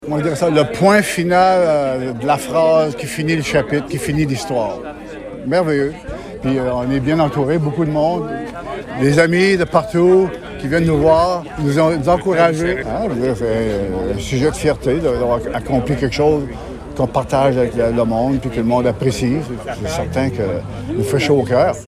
La Municipalité de Bouchette a procédé à l’inauguration officielle du nouveau Parc de la Chasse-Galerie, samedi, dernier, à l’occasion de la Fête nationale du Québec.